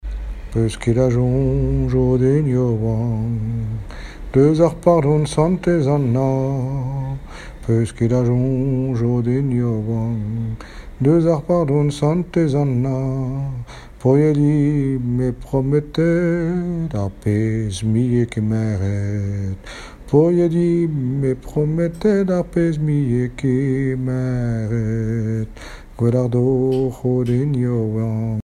Chansons populaires
Pièce musicale inédite